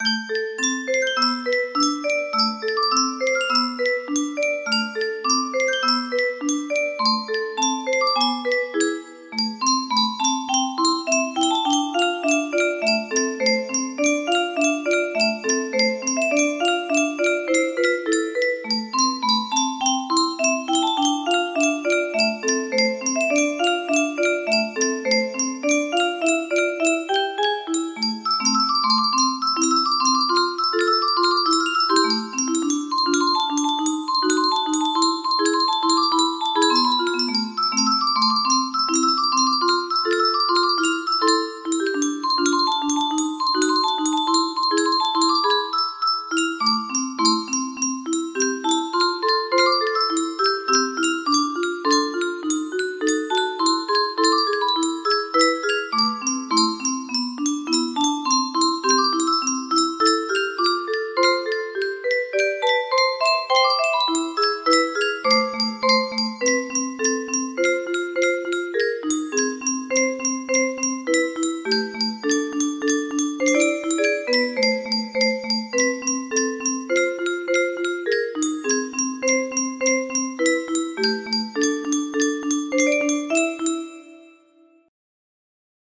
Key: A Minor
This is a Music Box version